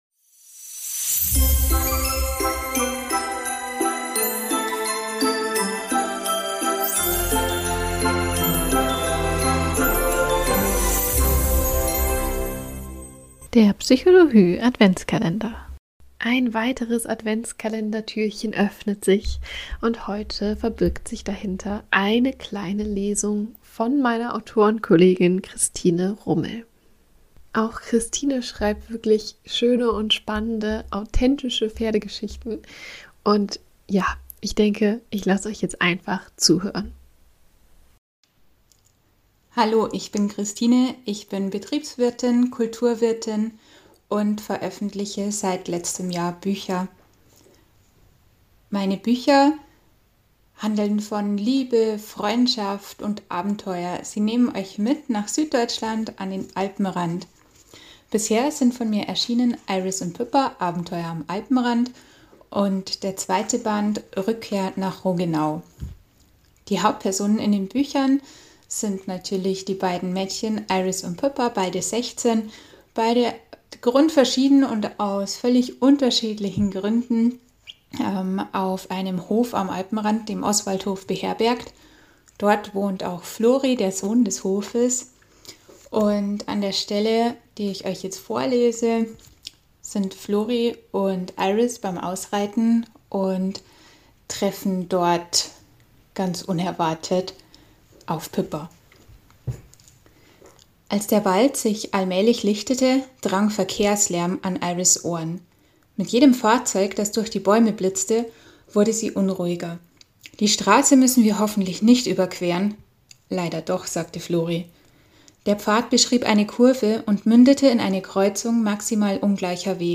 Traditionell wird an den Adventssonntagen unseres Adventskalenders gelesen: